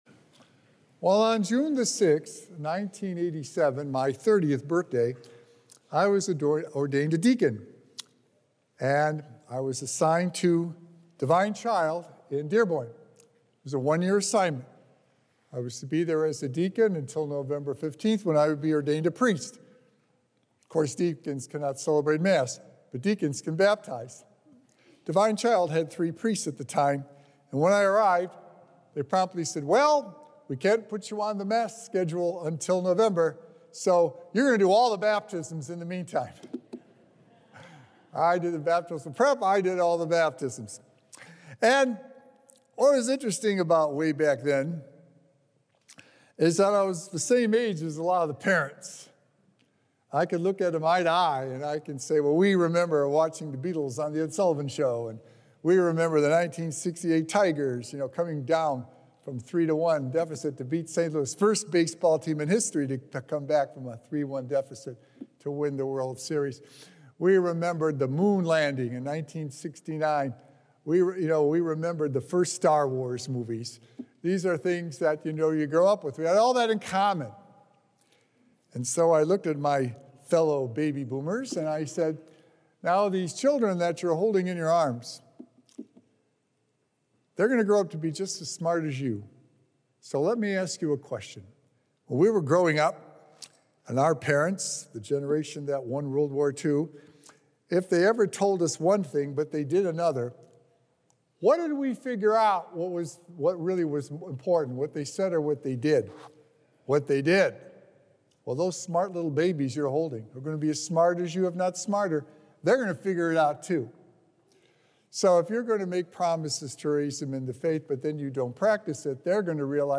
Sacred Echoes - Weekly Homilies Revealed
Recorded Live at St. Malachy Catholic Church on Sunday, March 3rd, 2025.